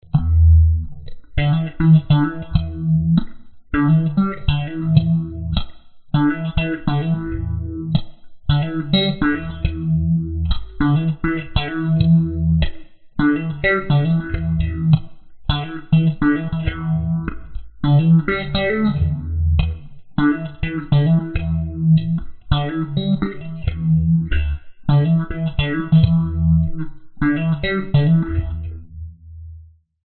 描述：一旦我在Ableton Live 7中创建了基本的放大器和门的设置，这样我就可以抓住我所追求的声音的尾巴部分，我继续通过各种声音处理技术滥用这些声音。
标签： 数字 毛刺 噪声 相位器
声道立体声